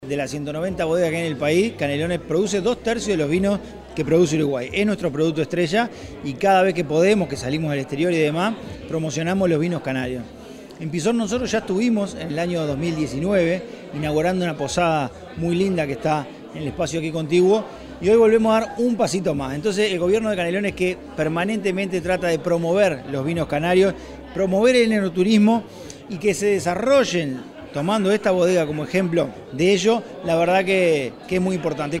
El Secretario General comentó que desde el 2019 el Gobierno de Canelones viene apoyando el proceso de crecimiento de la bodega.